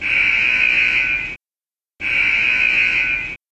* Annoying buzzer is back
warning_buzzer.ogg